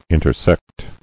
[in·ter·sect]